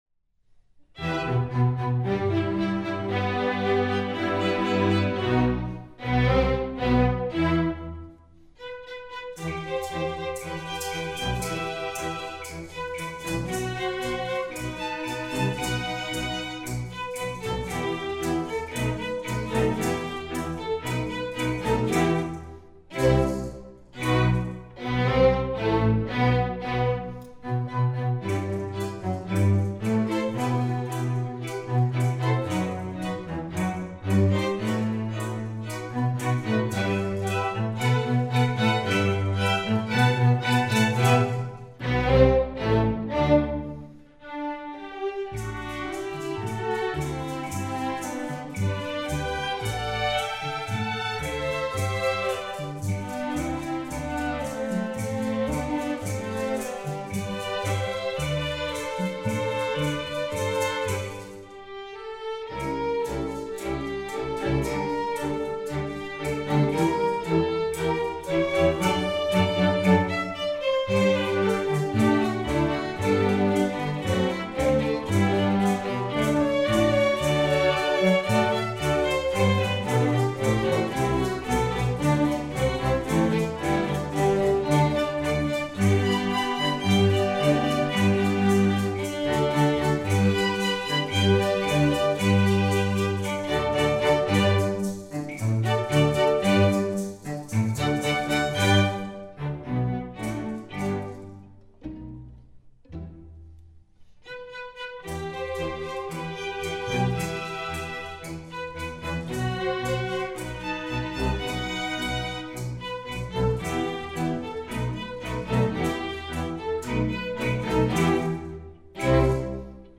Voicing: String Orchestra